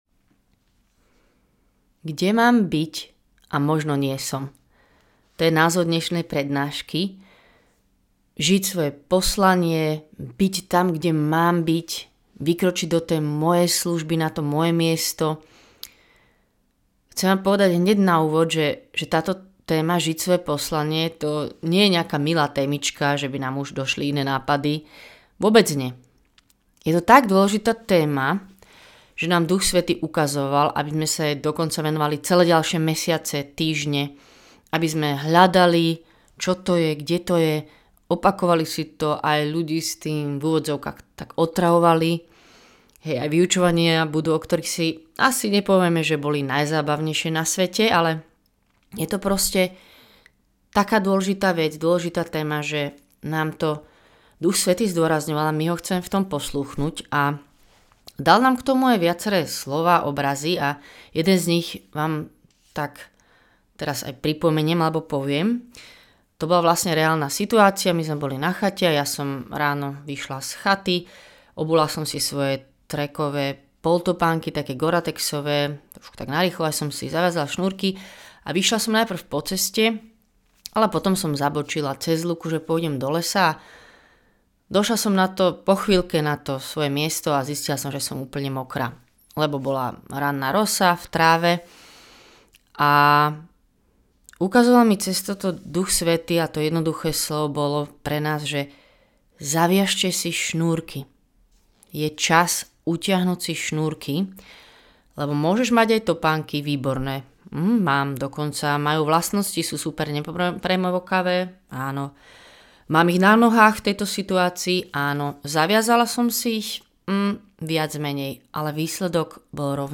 Slovo o konkrétnych pasciach a prekážkach, ktoré dokážeme tak ľahko prehliadnuť. Pôvodne (aj so svedectvami) zaznela táto prednáška na decembrovom Open Ebene v Lamači.